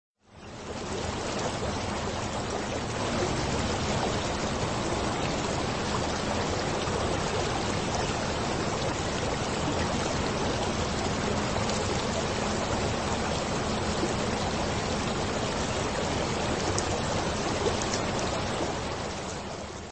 sea.mp3